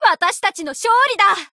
贡献 ） 协议：Copyright，作者： Cygames ，其他分类： 分类:富士奇石语音 您不可以覆盖此文件。